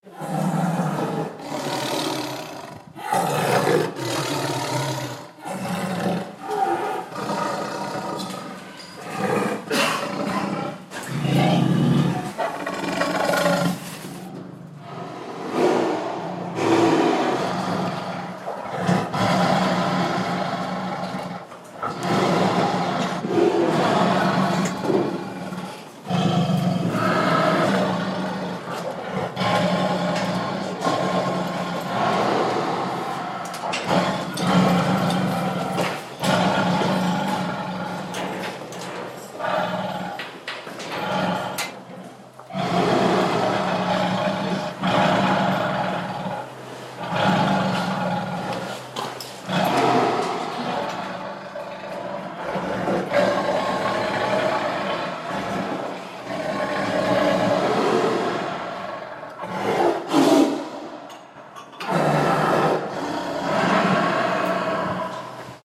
Angry Tiger 6089 (audio/mpeg)